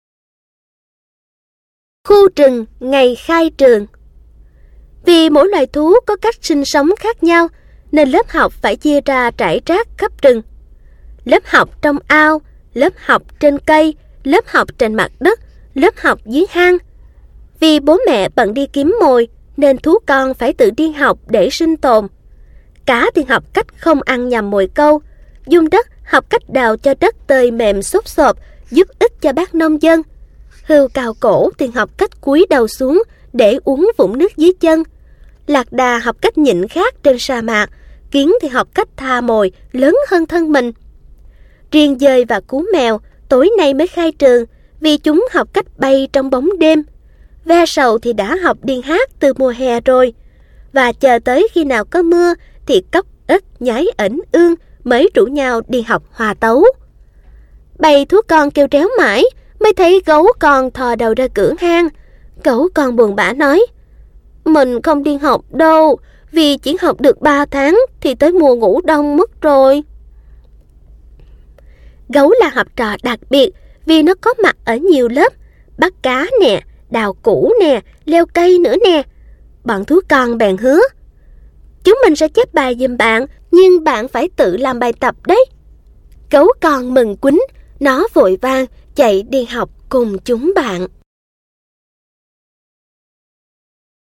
Sách nói | Xóm Đồ Chơi P8